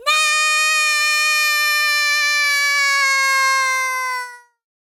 Worms speechbanks
nooo.wav